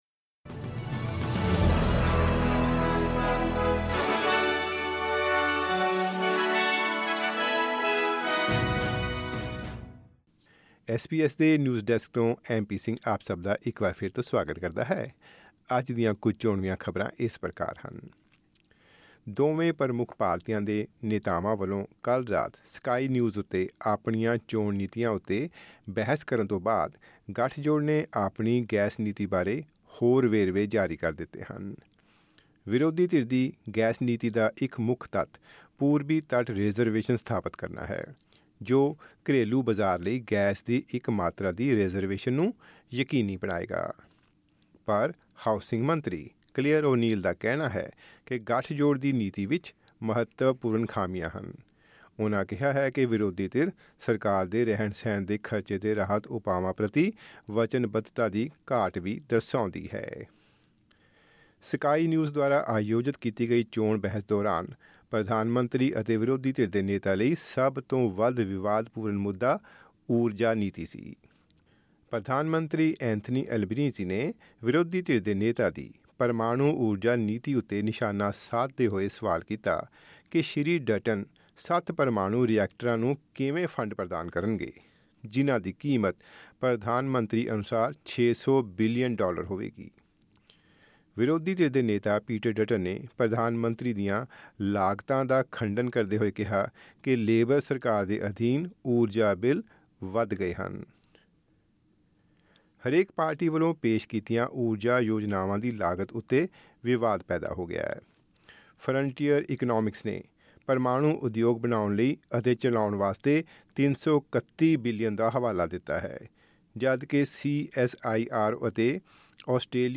ਖਬਰਨਾਮਾਂ: ਆਸਟ੍ਰੇਲੀਆ ਦੀਆਂ ਦੋਵੇਂ ਪ੍ਰਮੁੱਖ ਪਾਰਟੀਆਂ ਦੇ ਨੇਤਾਵਾਂ ਵੱਲੋਂ ਇਕ ਦੂਜੇ 'ਤੇ ਕੀਤੇ ਗਏ ਤਿੱਖੇ ਹਮਲੇ